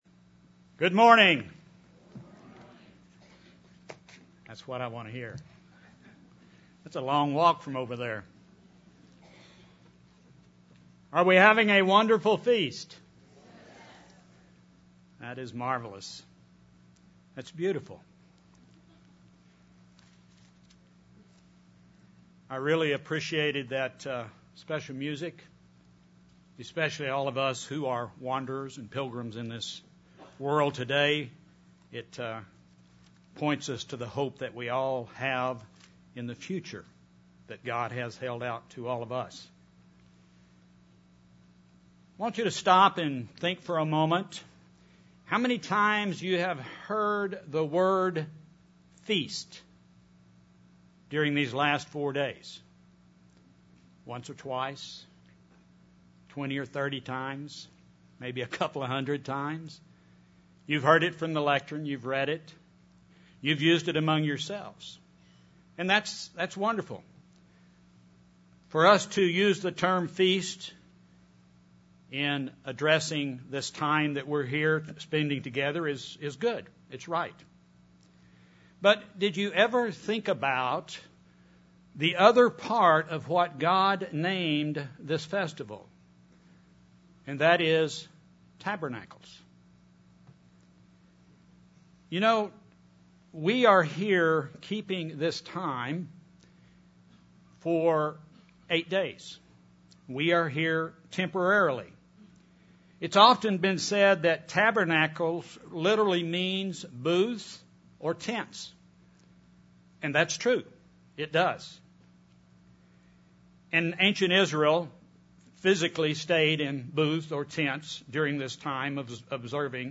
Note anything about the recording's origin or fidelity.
This sermon was given at the Branson, Missouri 2015 Feast site.